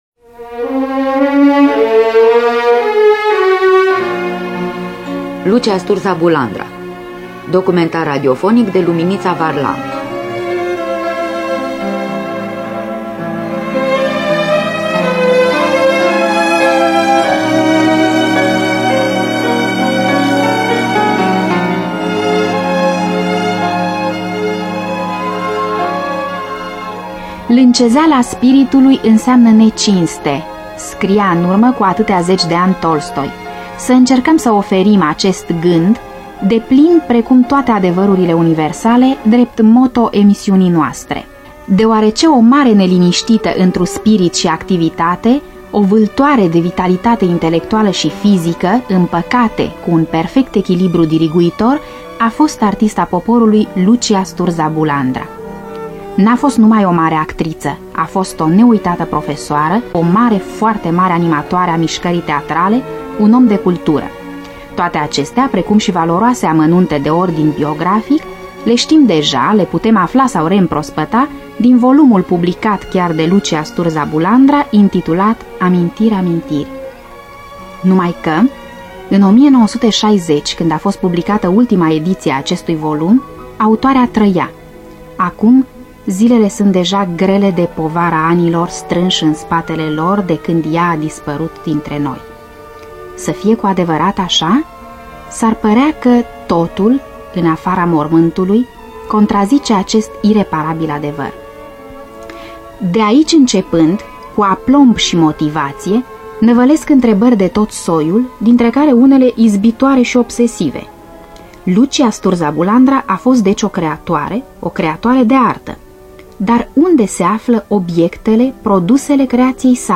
În distribuţie: Fory Etterle, Beate Fredanov, Dina Cocea, Radu Beligan, Octavian Cotescu, Victor Rebengiuc, Lucia Sturdza Bulandra.